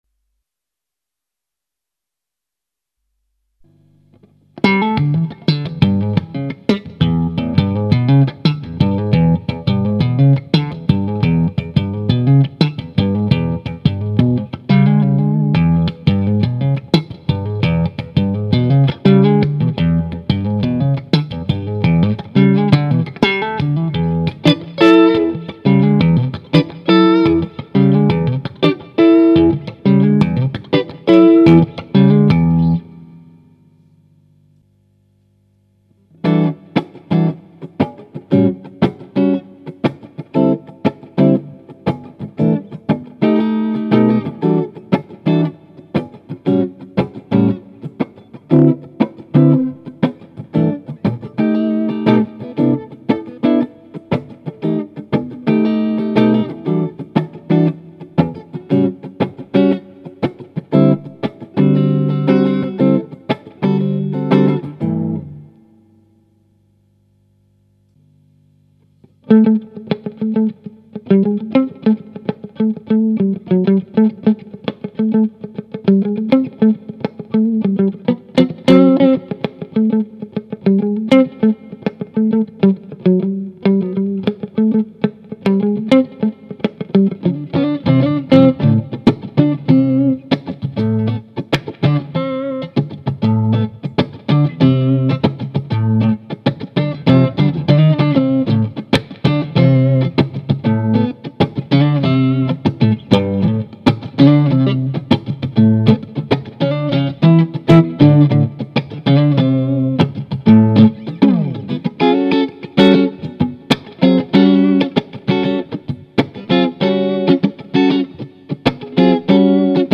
Voici une serie d'enregistrements qui ont été fait sur le Ten, l'ampli 10 watt de Pasqualiamps.
Le baffle utilisé est un "closed back" ave 2 HP Celestion G12-H. Les 3 premières plages ne contiennent aucun effet.
Plage 2 "Funk": Slap/Rythmique/Cocote "
Réglages Tactile Custom: Micro manche simple (splité) Tone 100 % - Volume 100 %
Réglage Pasqualiamps Ten: Tone 5.5 - Volume 1.5
Pas d'effets.